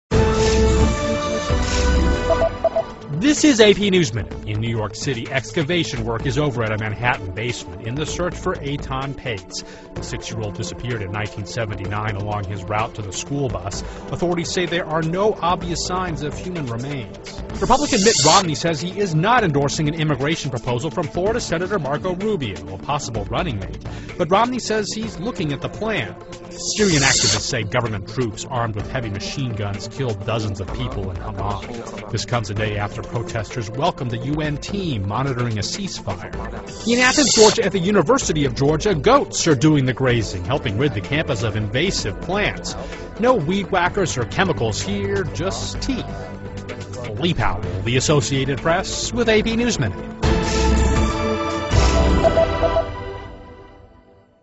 在线英语听力室AP 2012-04-27的听力文件下载,美联社新闻一分钟2012,英语听力,英语新闻,英语MP3-在线英语听力室